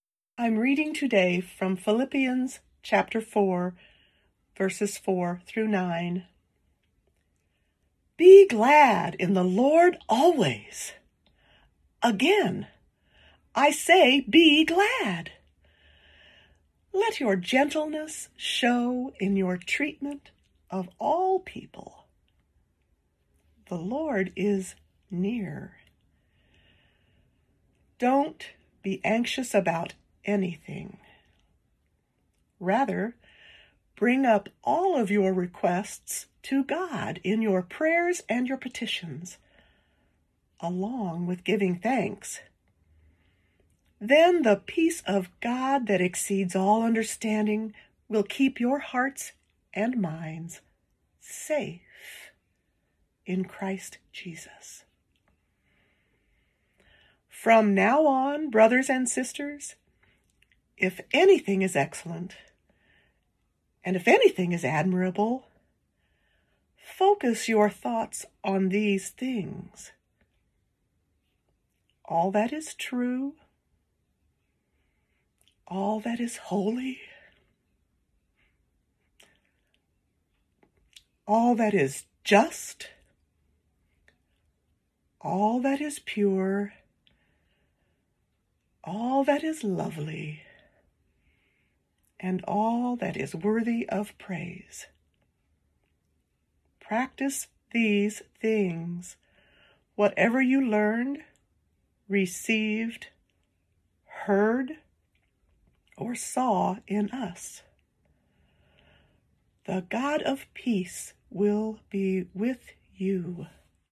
Scripture-Jan-18.wav